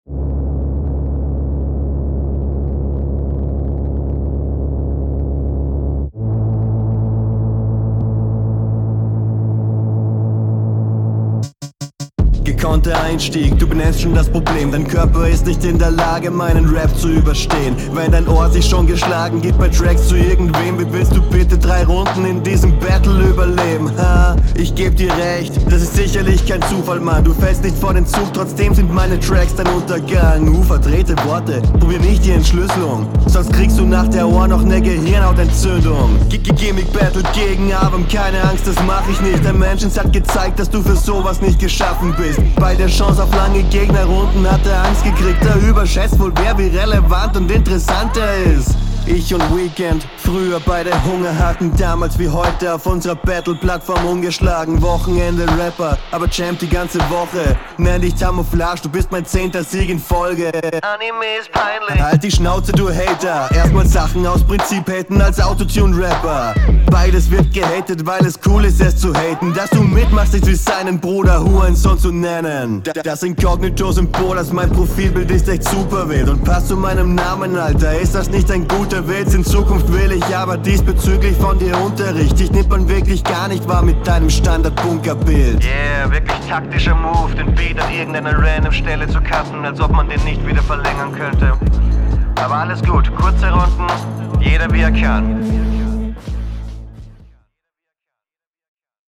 Mochte die Stelle mit "Halt die Schnauze du Hater" akustisch sehr.